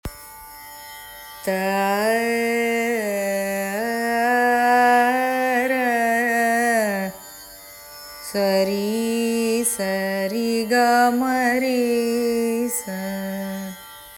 Apart from the raga quiz, we also have 'Do it Yourself' Swara exercises.